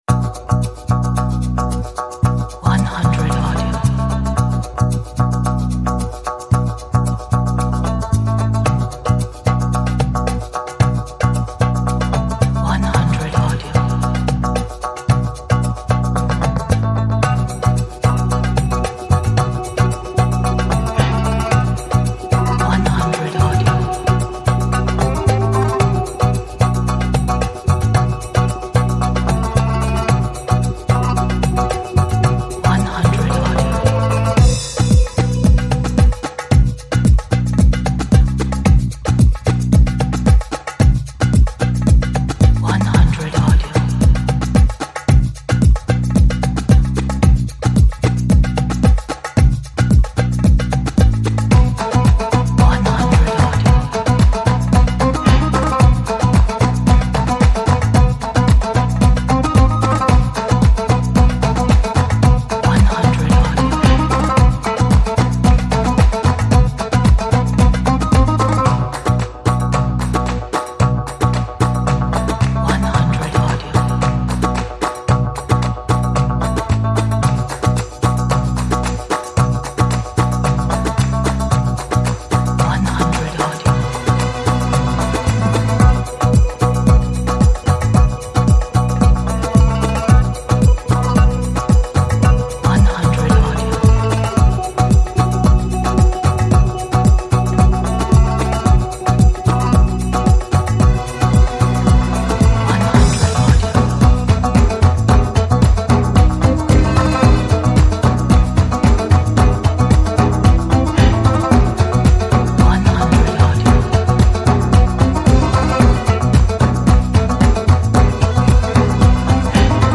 中东 旅游 纪录片